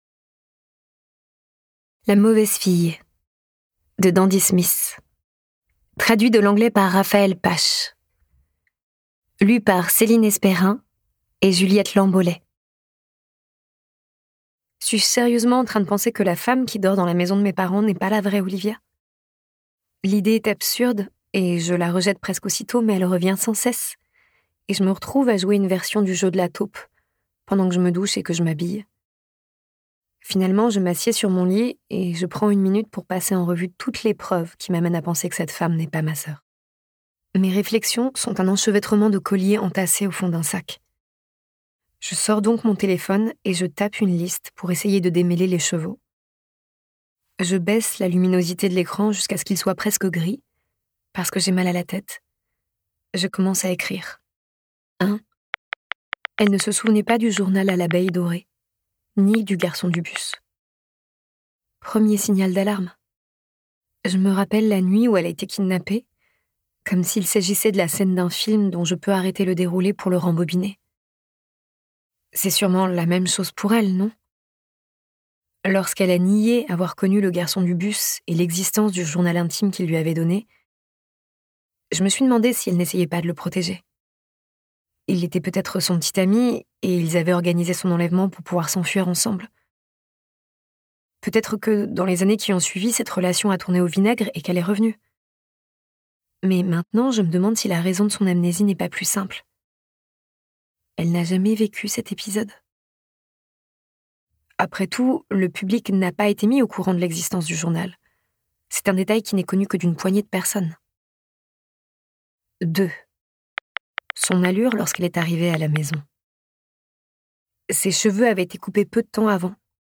Diffusion distribution ebook et livre audio - Catalogue livres numériques
Interprétation humaine Durée : 14H19 26 , 95 € Ce livre est accessible aux handicaps Voir les informations d'accessibilité